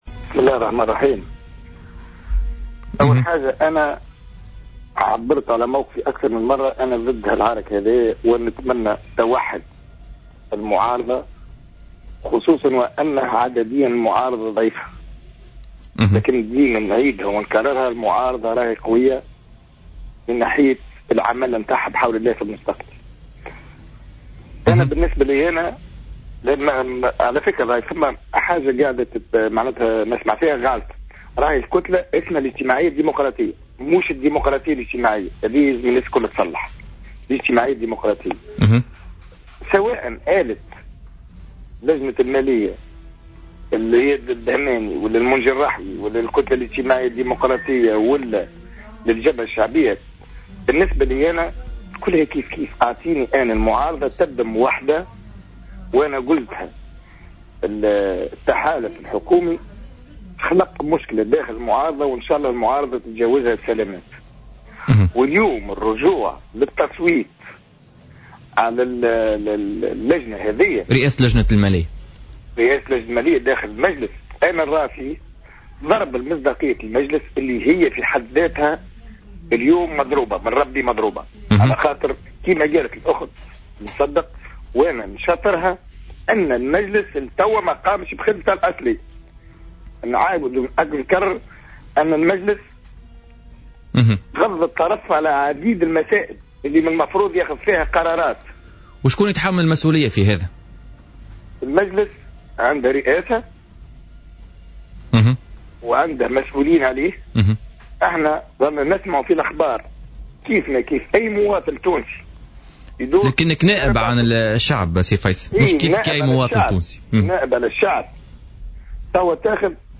اعتبر فيصل التبيني،نائب مجلس الشعب عن حزب صوت الفلاحين في مداخلة له اليوم في برنامج "بوليتيكا" الخلاف الحاصل حول رئاسة لجنة المالية أزمة مفتعلة.